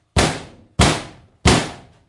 冲击、击打、摩擦 工具 " 钢板击打
Tag: 工具 工具 崩溃 砰的一声 塑料 摩擦 金属 冲击